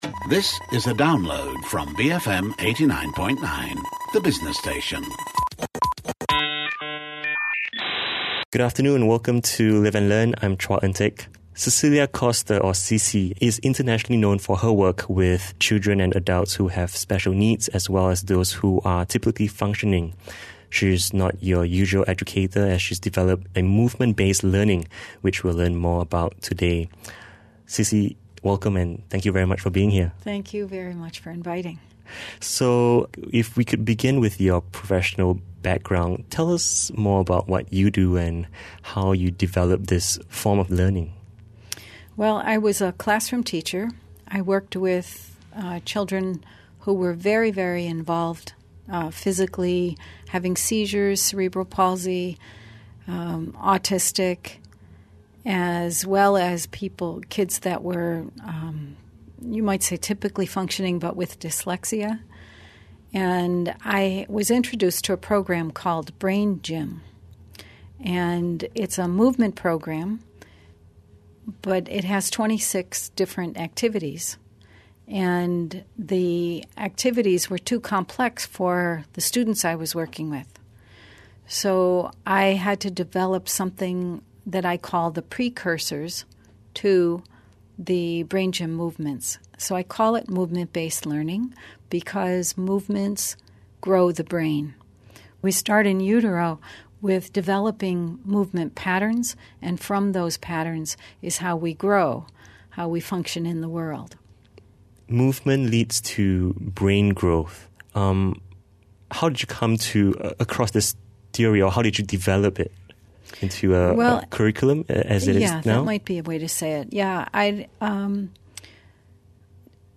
Listen to a recent BFM interview about MBL Play Podcast Rhythmic Movement Training Integrating reflexes that are triggered by external factors to allow growth to develop naturally.